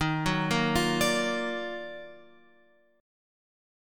D#mM9 chord {11 9 8 10 x 10} chord